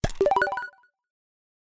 Implement click and level-up sounds, and integrate sound toggle in ResourceDisplay for enhanced user experience.
levelup.mp3